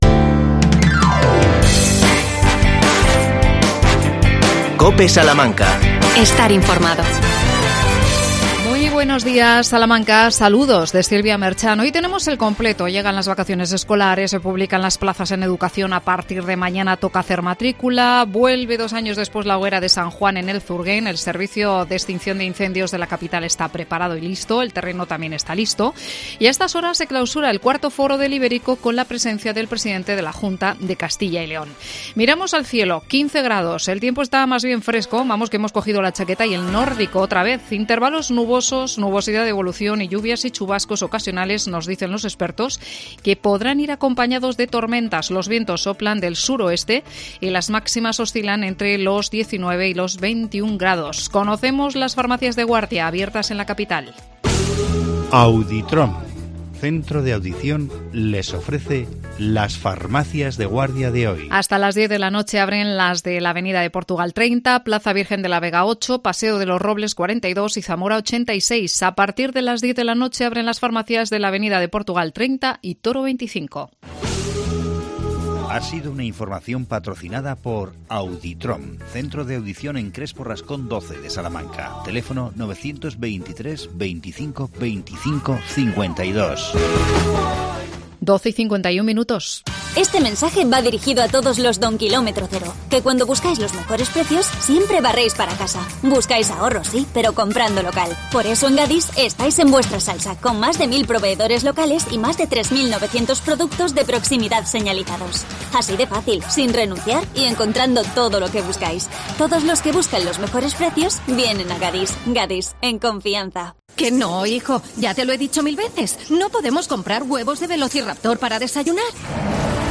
Agricultores contra el cambio climático. Llega la hoguera de San Juan del Zurguén. Entrevistamos